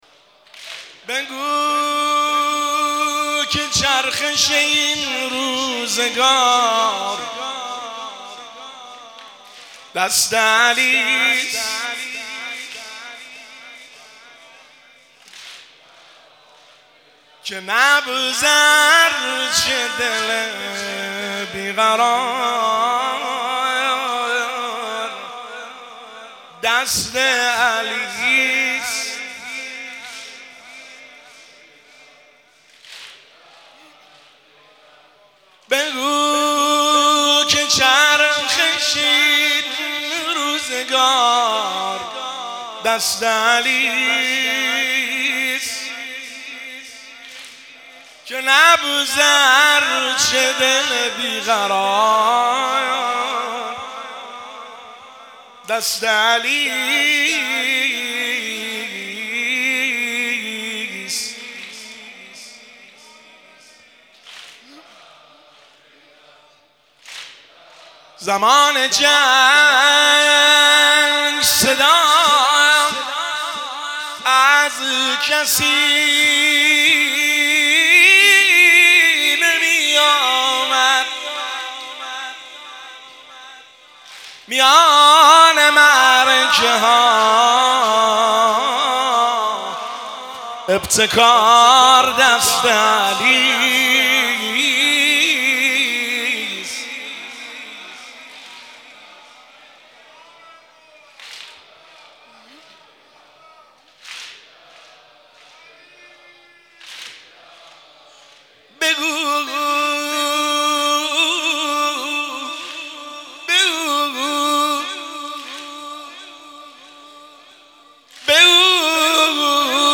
شعرخوانی – شب اول فاطمیه دوم 1403